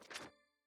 map_open_lowfreq.wav